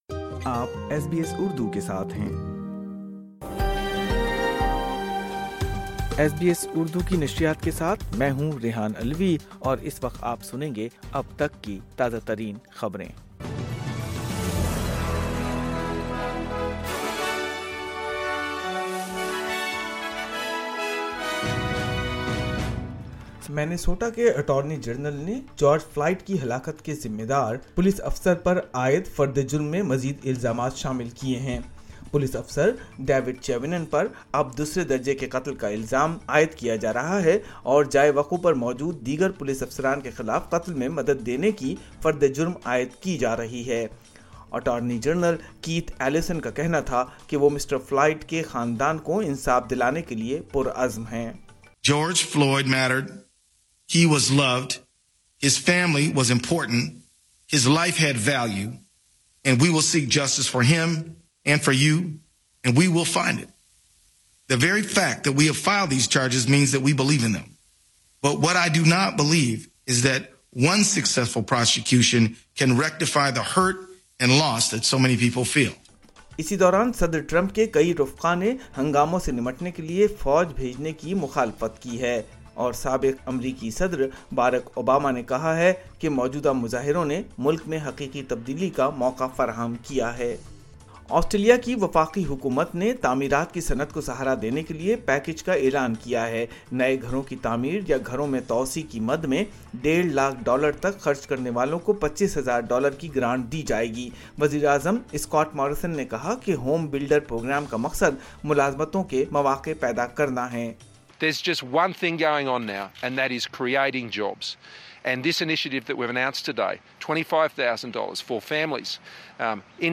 news_4_jun20.mp3